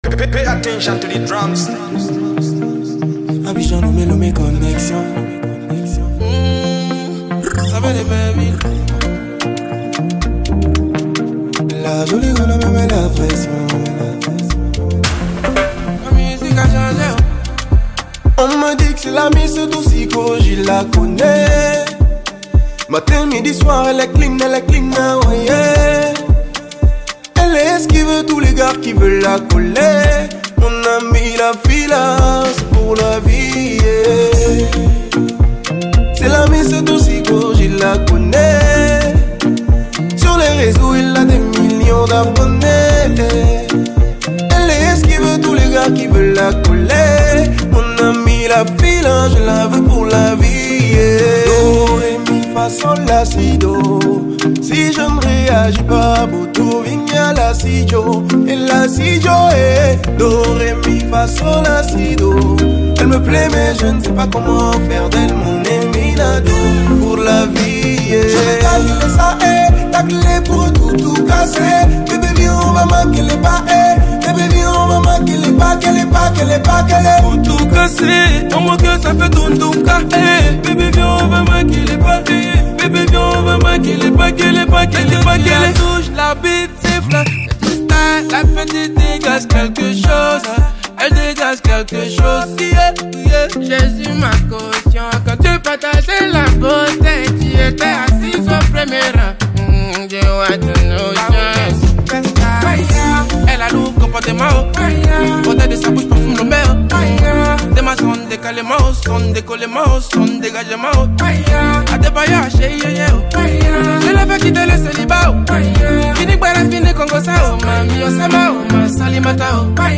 | Afrobeat